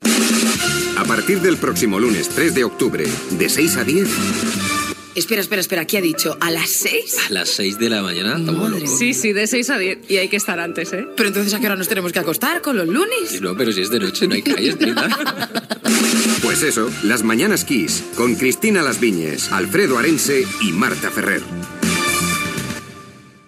Promoció de l'inici de "Las mañanas Kiss", amb nous presentadors